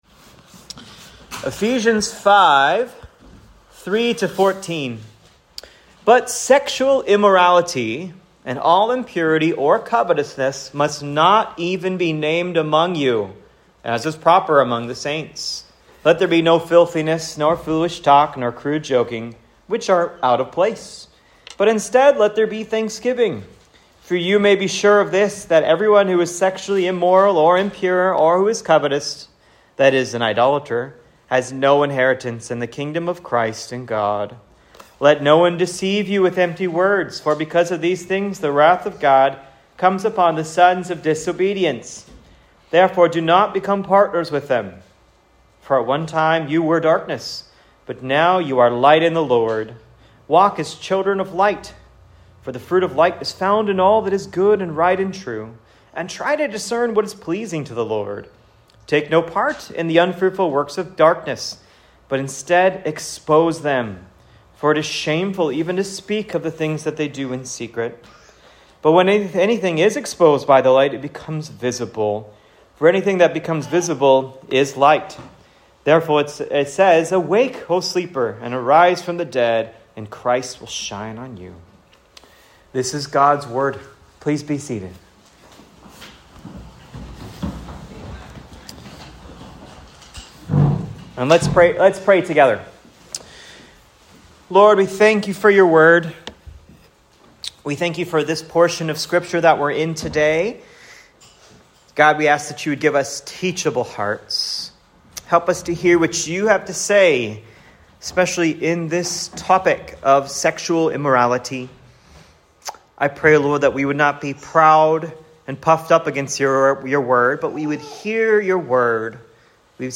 3 Motivations to Help us Say No to SEXUAL SIN: Ephesians 5:3-14 Sermon Outline